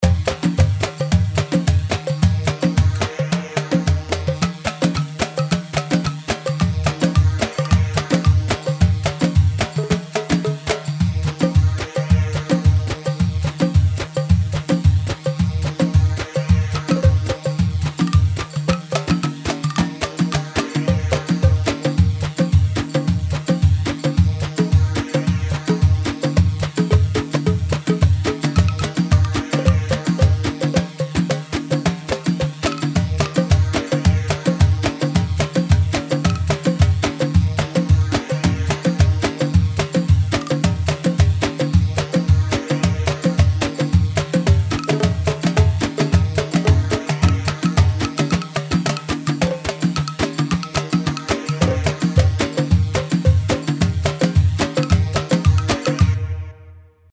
Dosari 3/4 164 دوسري